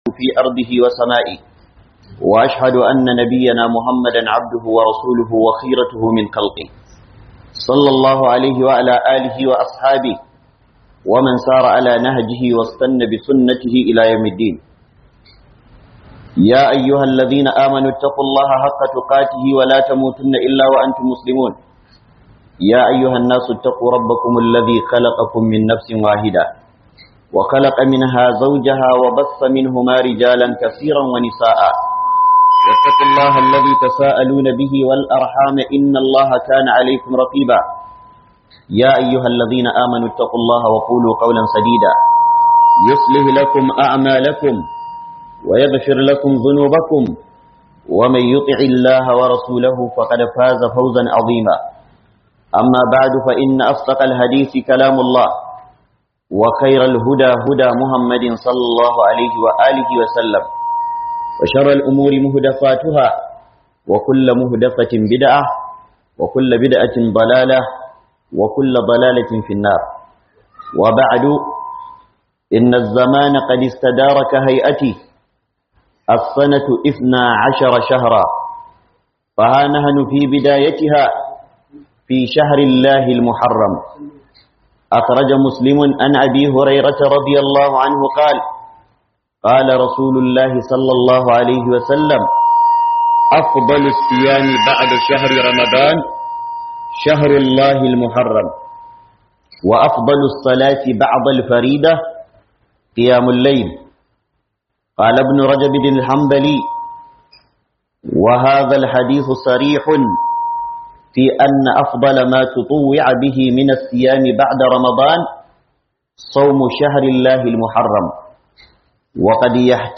TASUA DA ASHURA 1447 - Huduba